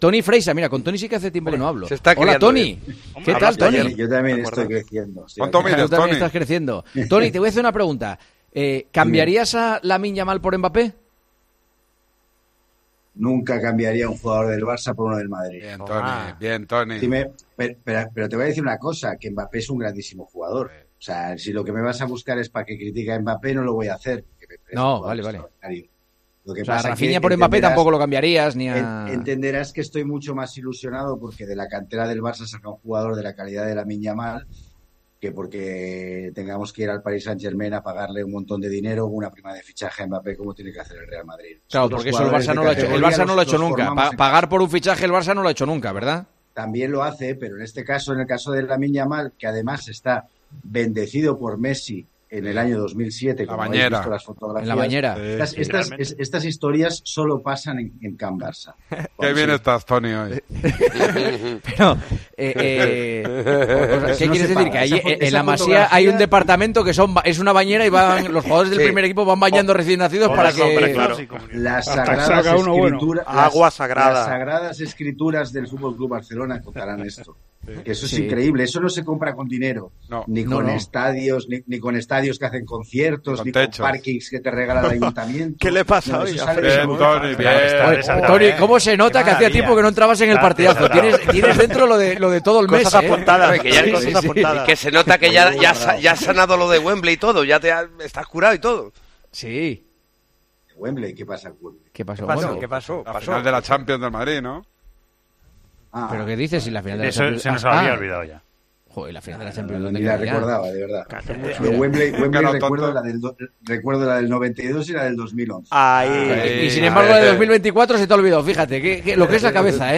Toni Freixa, actual comentarista de El Partidazo de COPE, y excandidato a la presidencia del Barcelona, estuvo este miércoles en los micrófonos de COPE para analizar la actualidad veraniega del Barcelona.